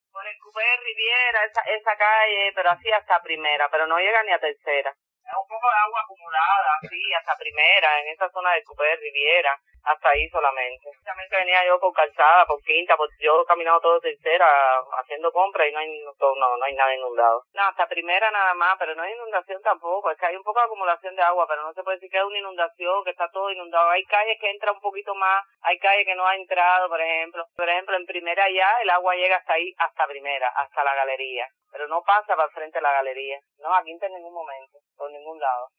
Inundaciones leves en La Habana, dicen residentes